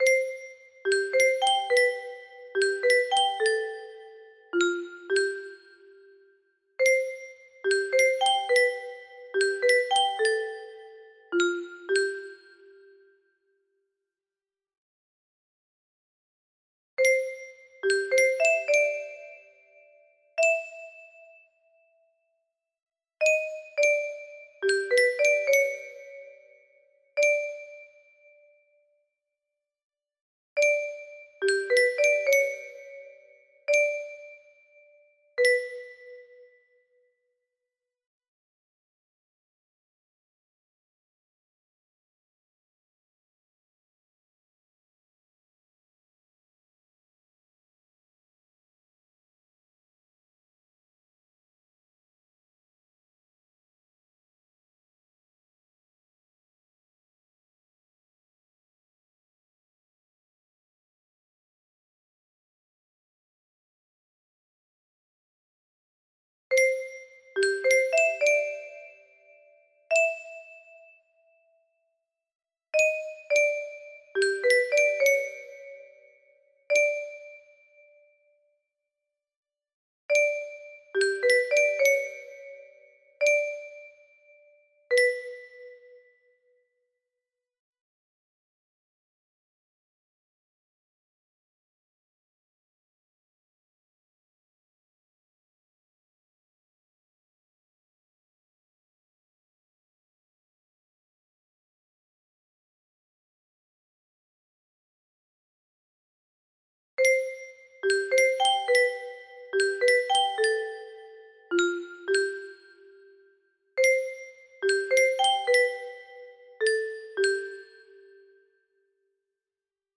Specially adapted for 30 notes